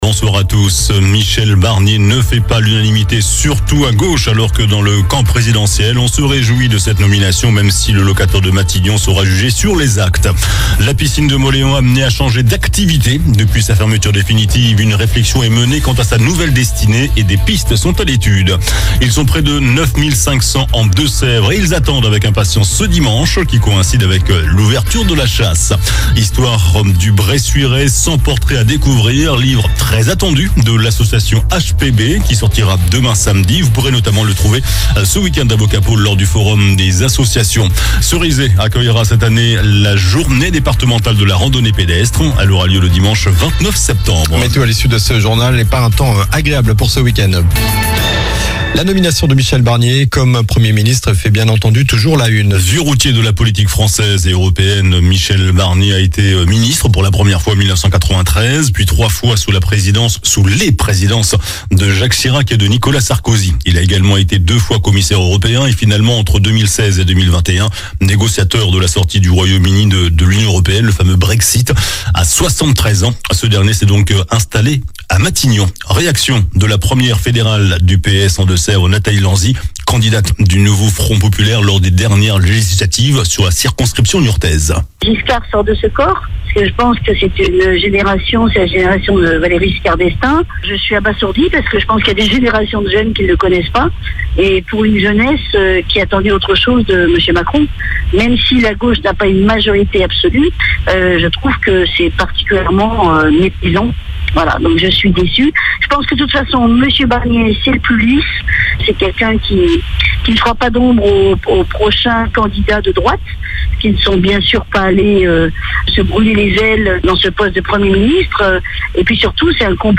JOURNAL DU VENDREDI 06 SEPTEMBRE ( SOIR )